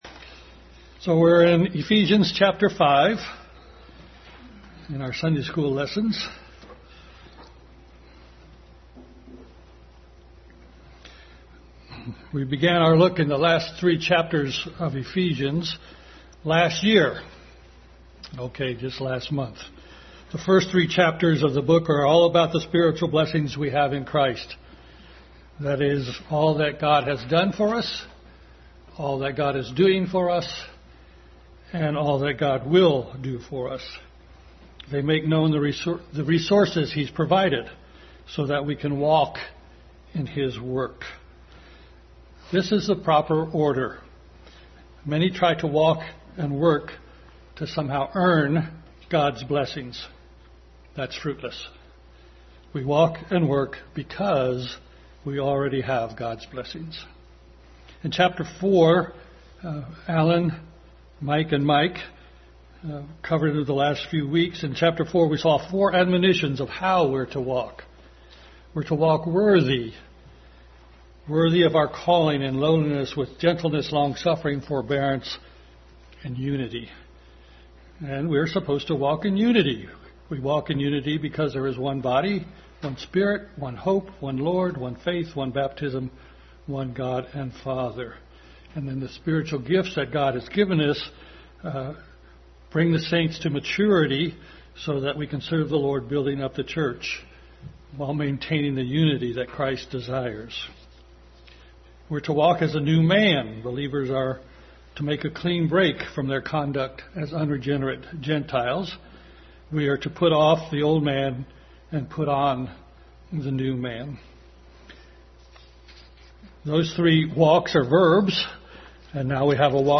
Ephesians 5:1-21 Service Type: Family Bible Hour Continued study in Ephesians.